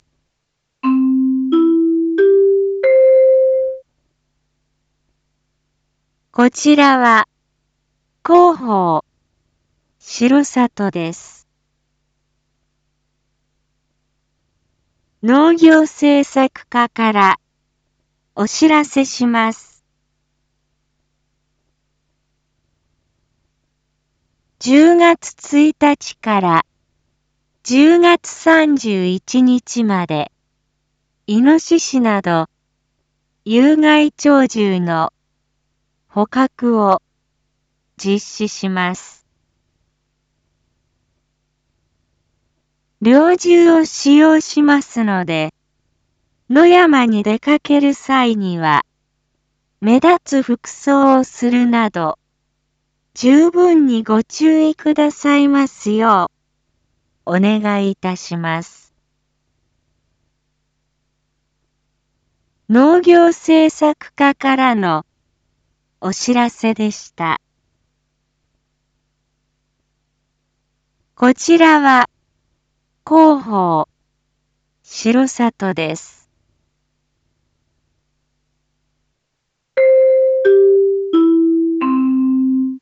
一般放送情報
Back Home 一般放送情報 音声放送 再生 一般放送情報 登録日時：2023-10-27 19:01:24 タイトル：有害鳥獣捕獲について インフォメーション：こちらは、広報しろさとです。